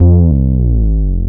12BASS01  -R.wav